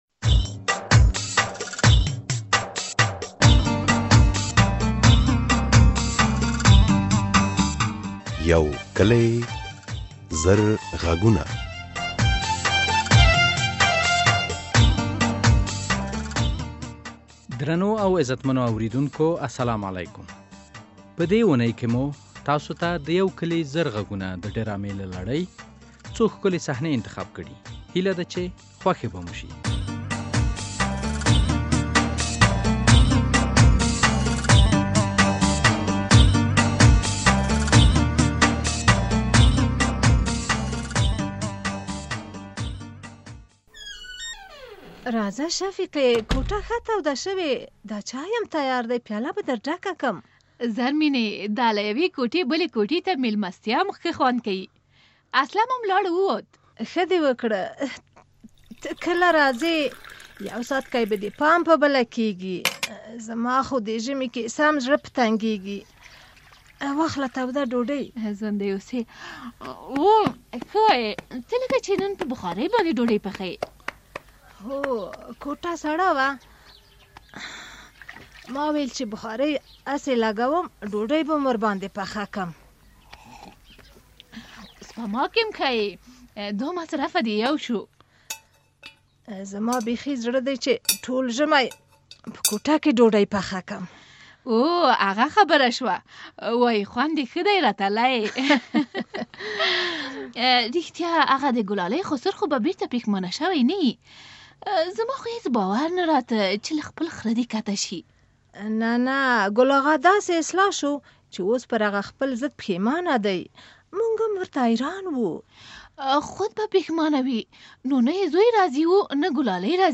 د یو کلې زرغږونو ډرامې ۱۹۷ برخه، اوری چې ګلالۍ خپل لېوره که بل ځای واده کېږي؟ ایا مرسل په کانګور ازموینه ...